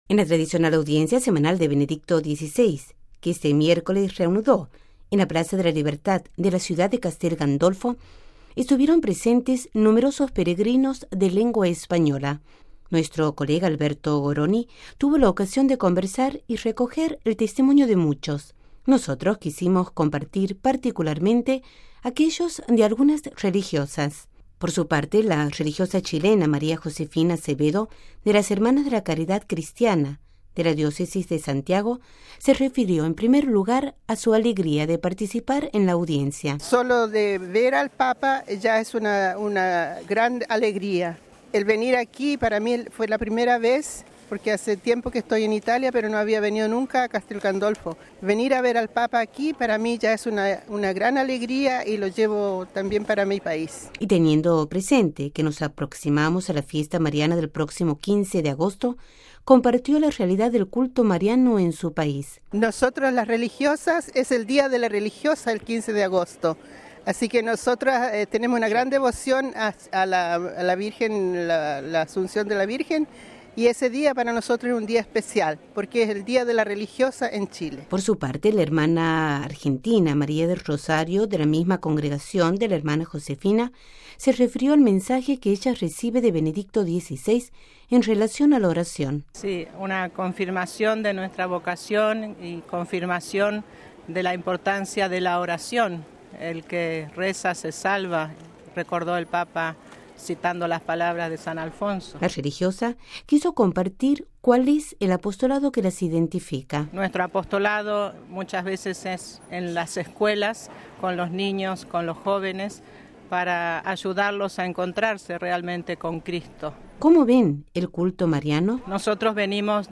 (RV).- (Con Audio) En el marco de la reanudación de la Audiencia General que había quedado suspendida durante el mes de julio, Benedicto XVI se refirió a la importancia de la oración trayendo las enseñanzas que san Alfonso María Ligorio aporta en el tratado que escribió en 1759: El gran medio de la Oración. La Audiencia General tuvo lugar en la plaza central de la localidad lacial de Castelgandolfo.